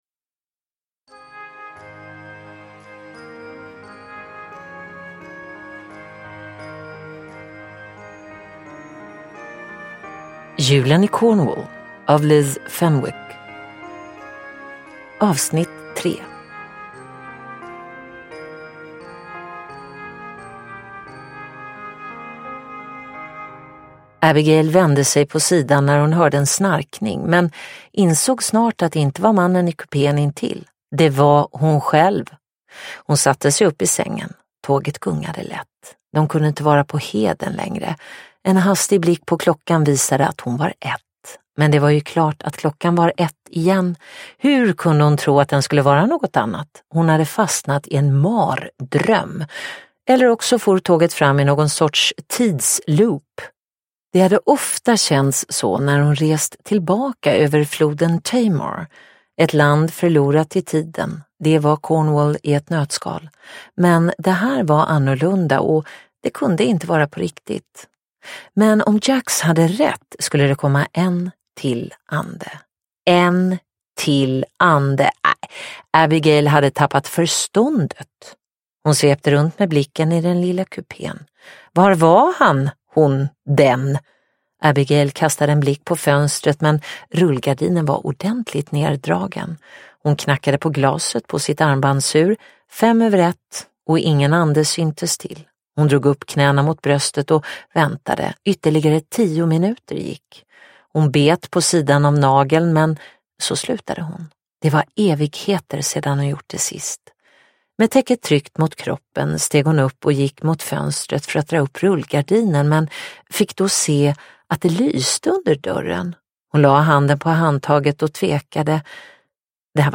Julen i Cornwall - Del 3 : En julsaga – Ljudbok – Laddas ner